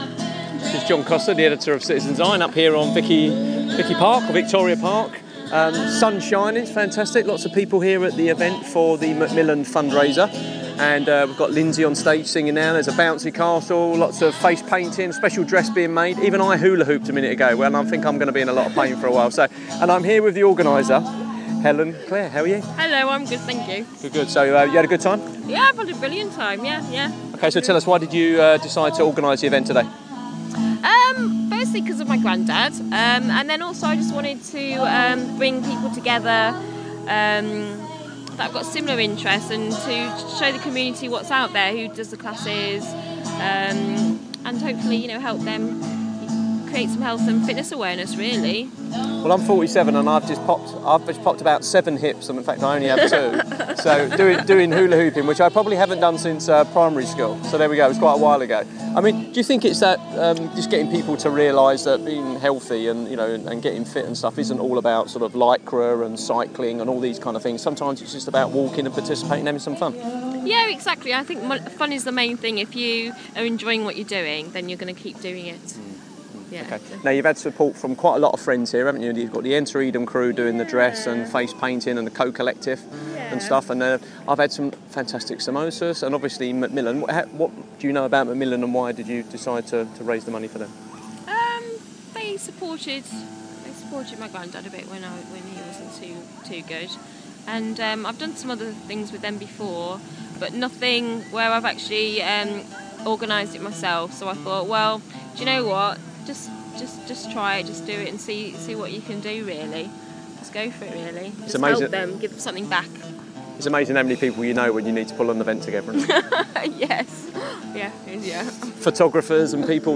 Short interview